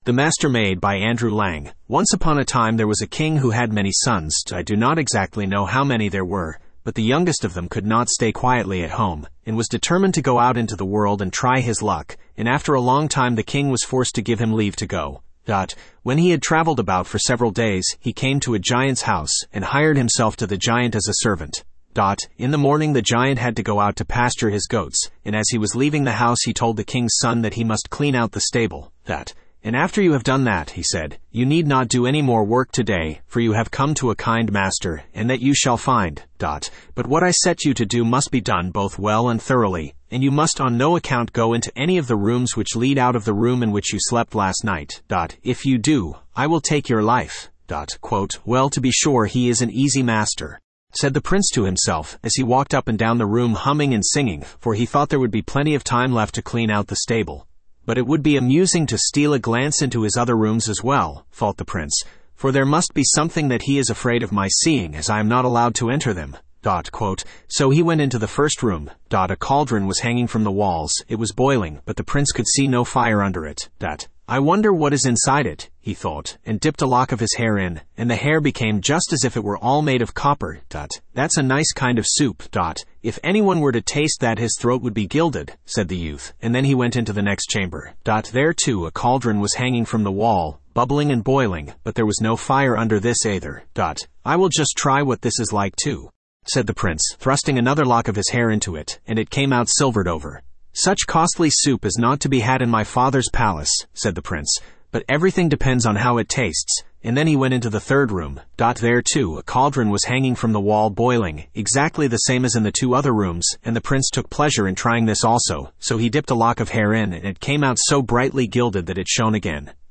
Spoken Ink « The Blue Fairy Book The Master-maid Studio (Male) Download MP3 Once upon a time there was a king who had many sons.